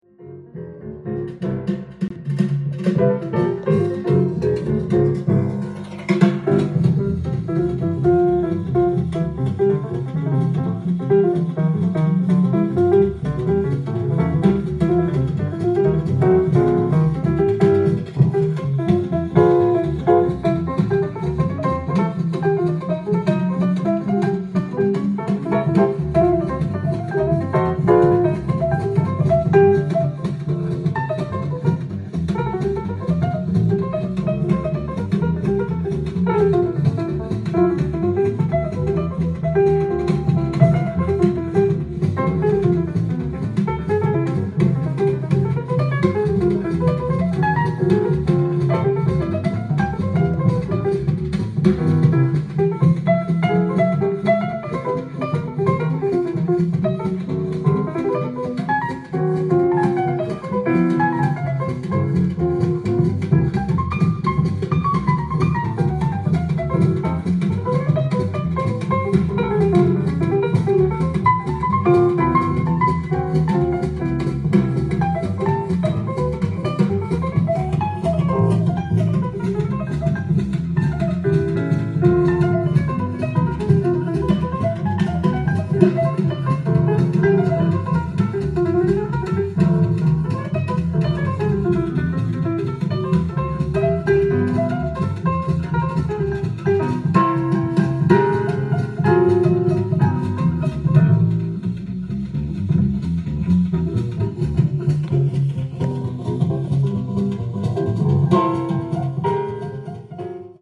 ジャンル：JAZZ-ALL
店頭で録音した音源の為、多少の外部音や音質の悪さはございますが、サンプルとしてご視聴ください。